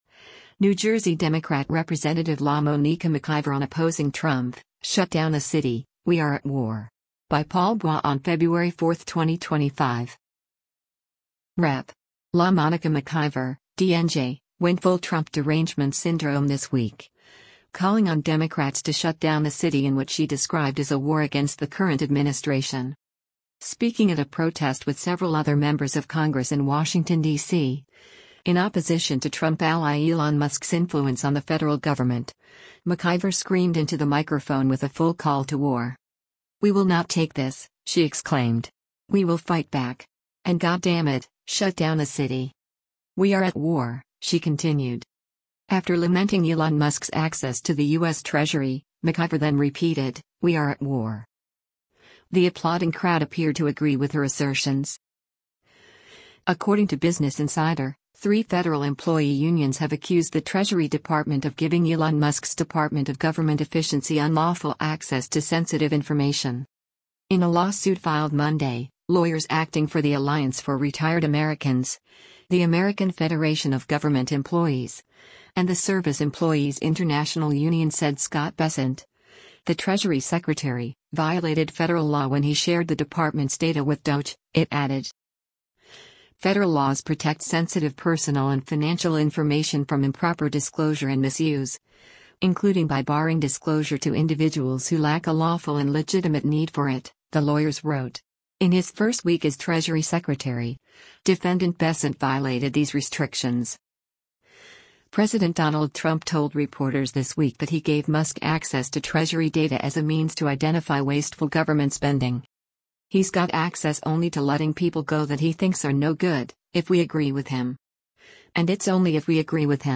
Speaking at a protest with several other members of Congress in Washington, DC, in opposition to Trump ally Elon Musk’s influence on the federal government, McIver screamed into the microphone with a full call to war.
The applauding crowd appeared to agree with her assertions.